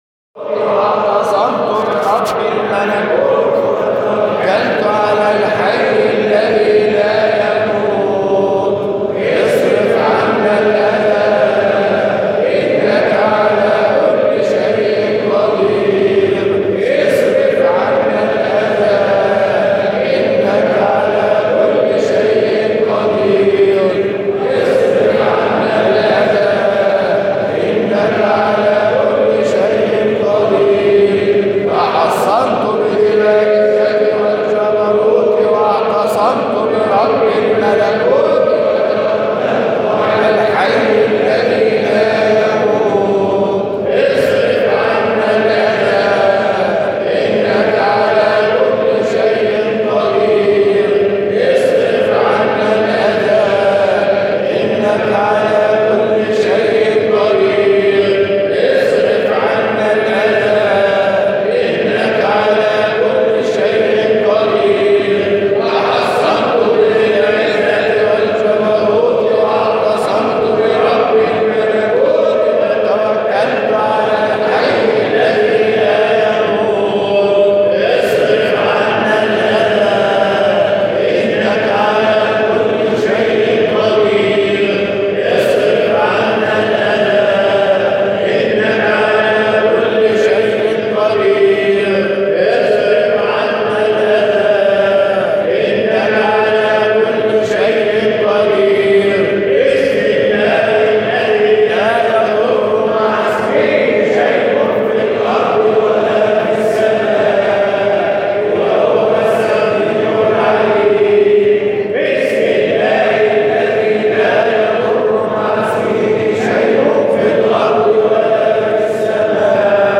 مقاطع من احتفالات ابناء الطريقة الحامدية الشاذلية بمناسباتهم